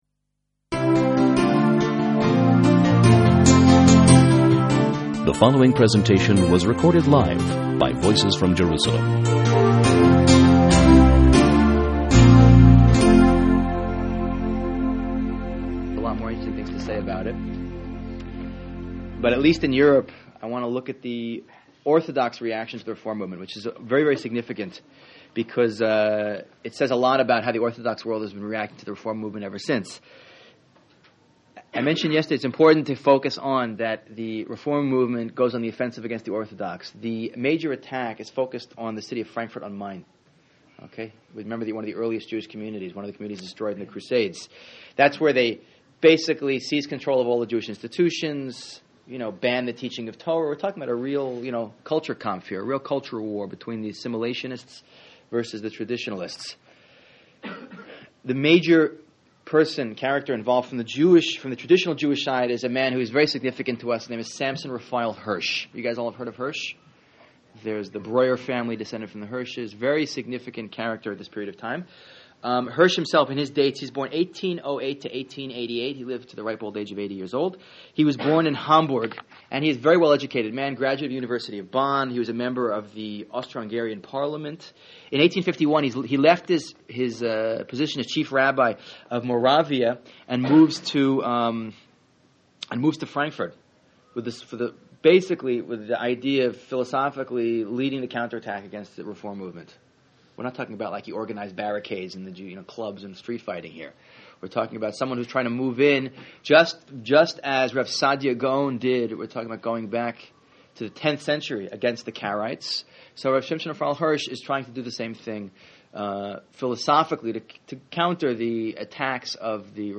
Outline or Thoughts on this Lecture Commenting is not available in this channel entry.